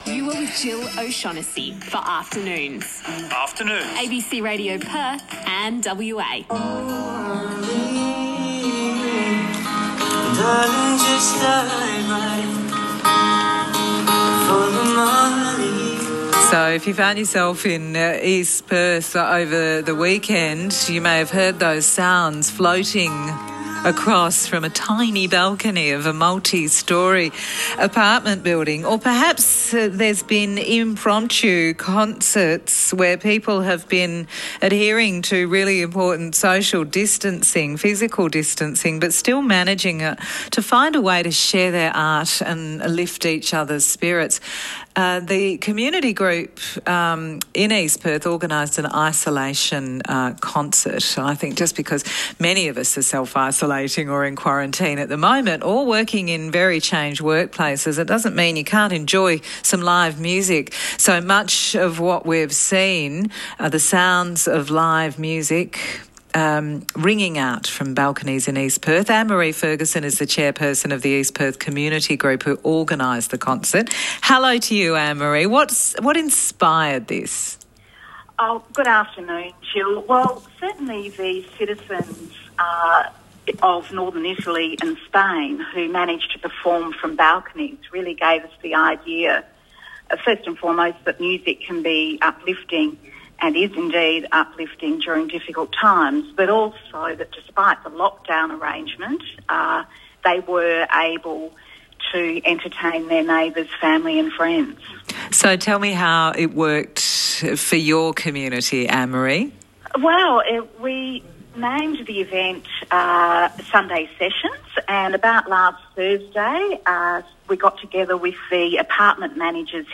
Sunday Sessions Interview